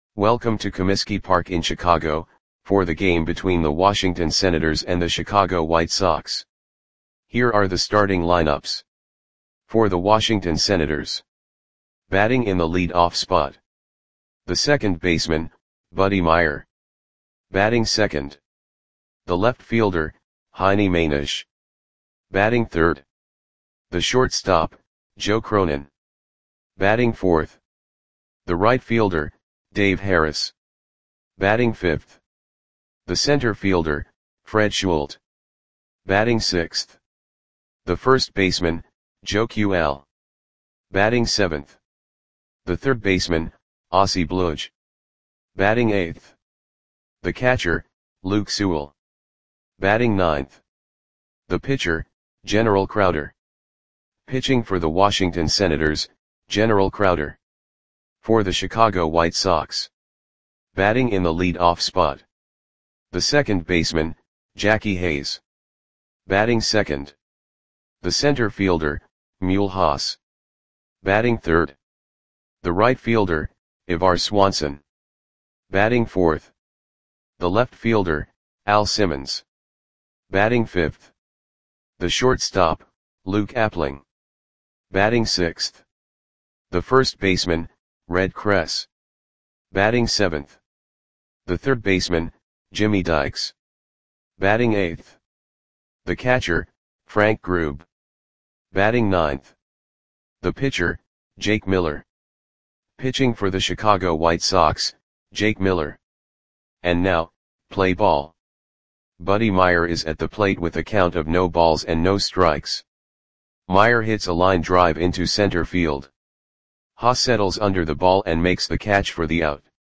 Audio Play-by-Play for Chicago White Sox on June 22, 1933
Click the button below to listen to the audio play-by-play.